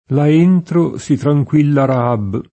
tranquillare v.; tranquillo [trajkU&llo] — nell’uso mod., più com. tranquillizzare (pres. tranquillizzo [trajkUill&zzo]), spec. riferito a persone nel sign. di «rassicurare, liberare da preoccupazioni» — sempre tranquillare, d’uso oggi lett., con sensi diversi non più com. o coi valori più generici che il verbo aveva in passato, per es. tranquillare «rendere sicuro», tranquillarsi «godere di tranquillità»: per tranquillar la via [per trajkUill#r la v&a] (Dante); là entro si tranquilla Raab [